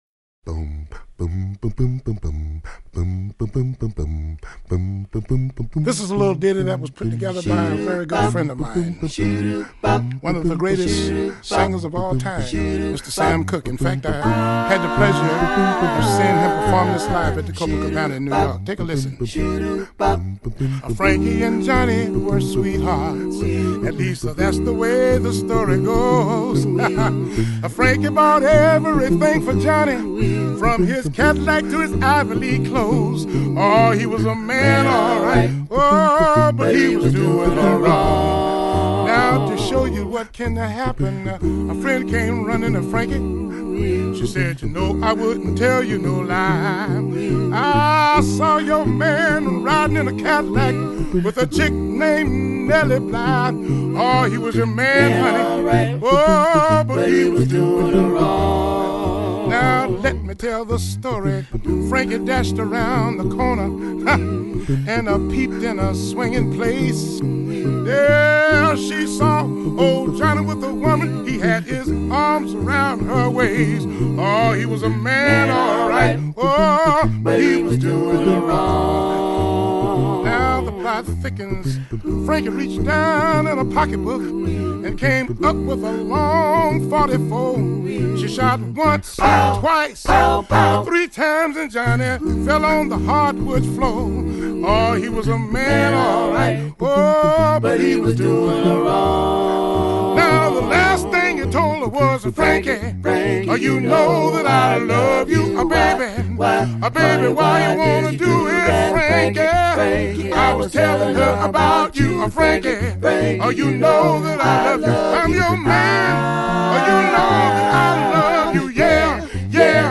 recorded on good ol’ warm analog